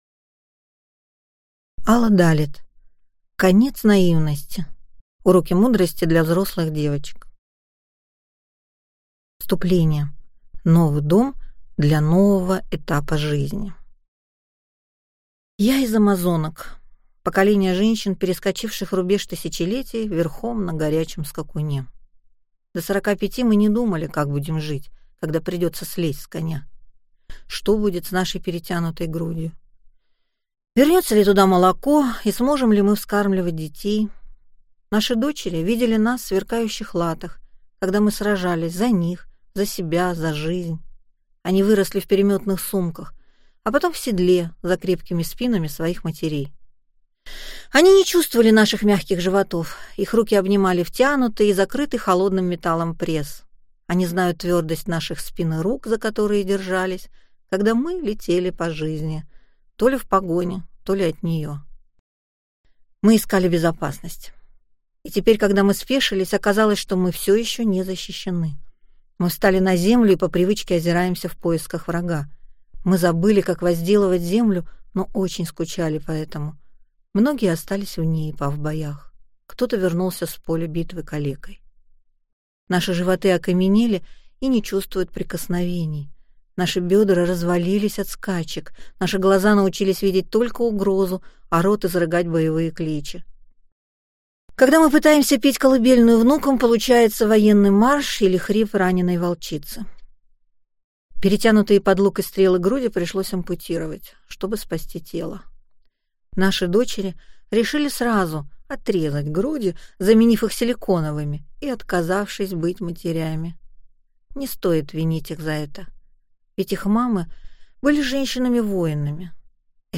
Аудиокнига Конец наивности. Уроки мудрости для взрослых девочек | Библиотека аудиокниг